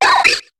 Cri de Riolu dans Pokémon HOME.